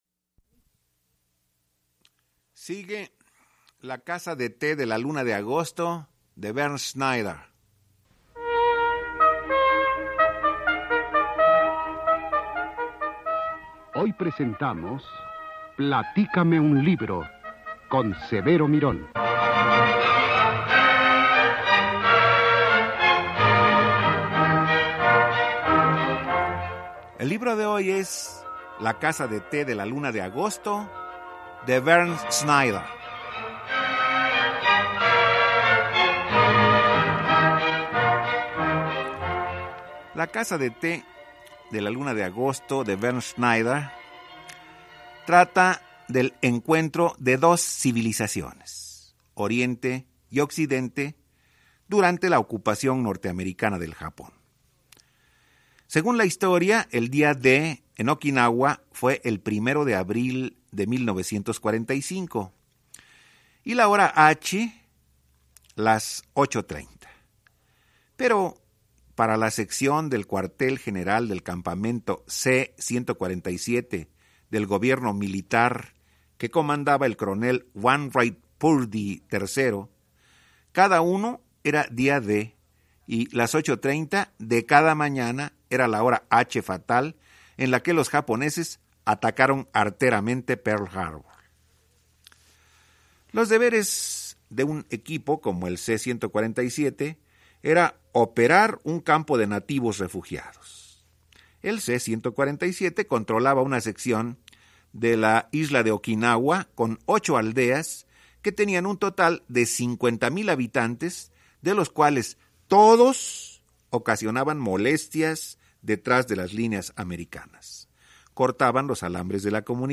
“Platícame un libro”, transmitido en 1999